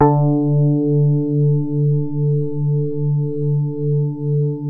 JAZZ SOFT D2.wav